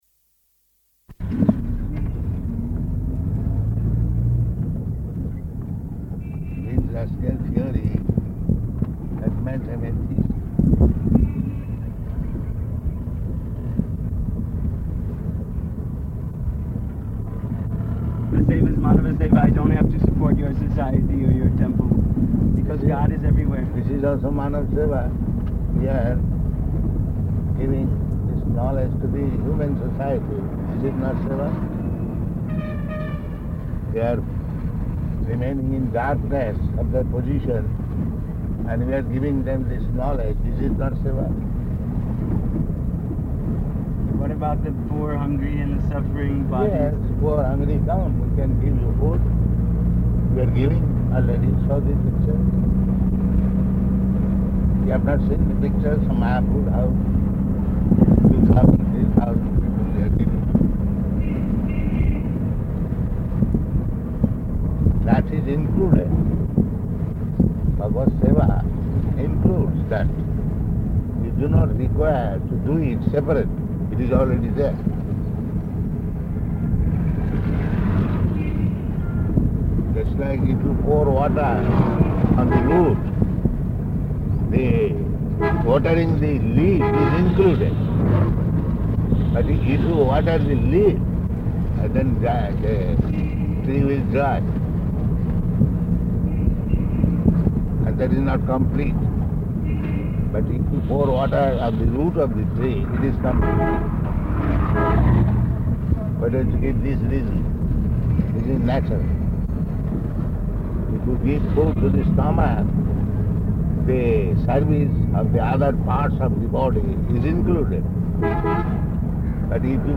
Morning Walk [partially recorded]
Type: Walk
Location: Hyderabad